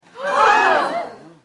THAT_crowd_gasp.mp3